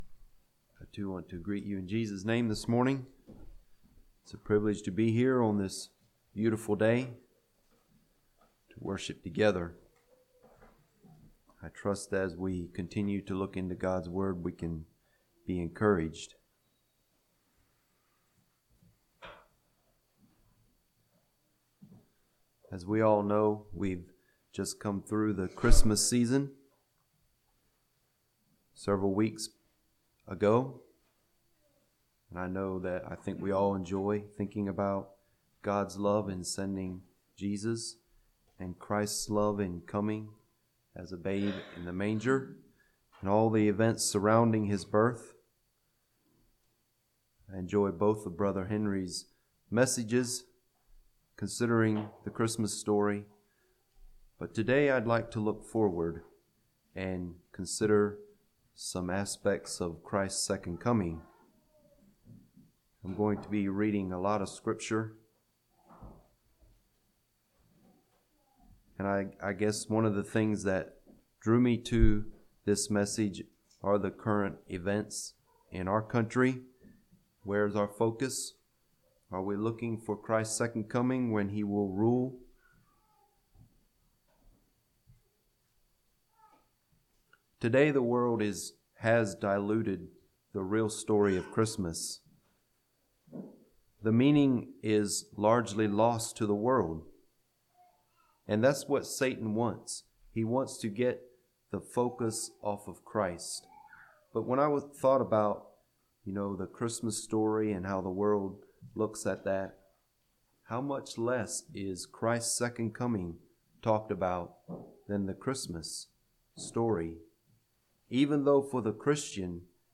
2021 Sermon ID